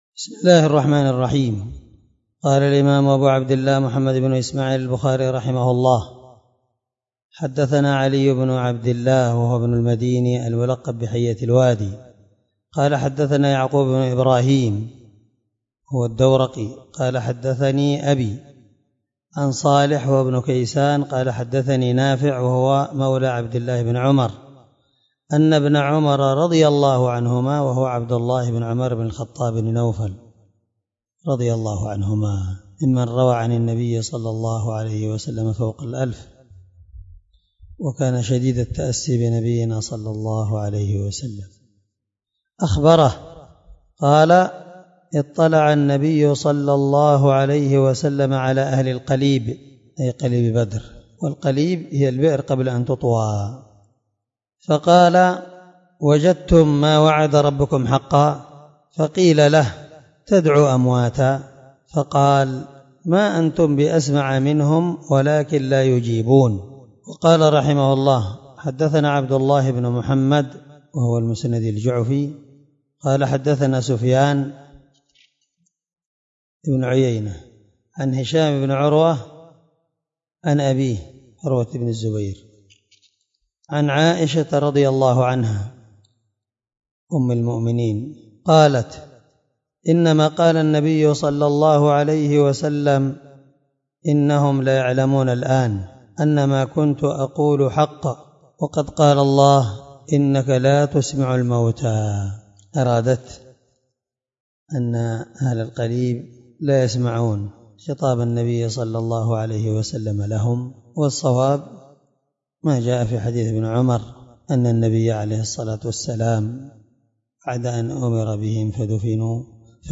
790الدرس 63من شرح كتاب الجنائز حديث رقم(1370-1374 )من صحيح البخاري
دار الحديث- المَحاوِلة- الصبيحة.